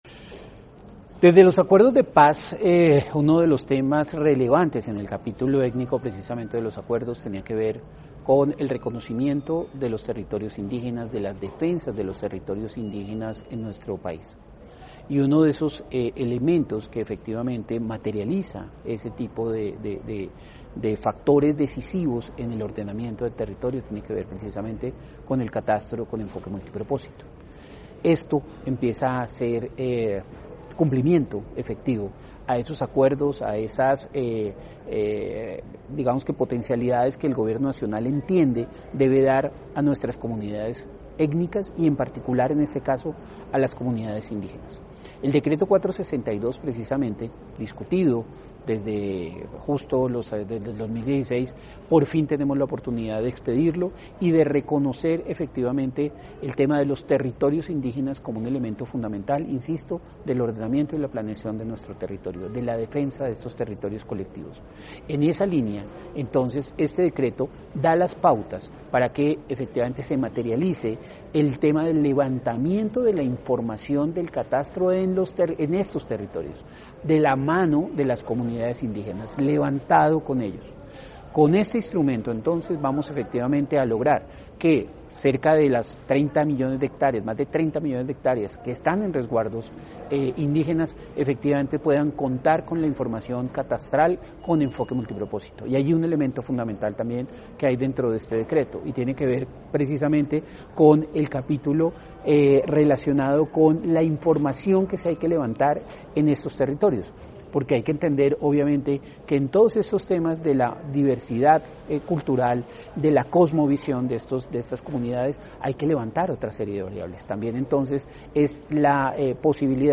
Gustavo Marulanda, director general del Instituto Geográfico Agustín Codazzi.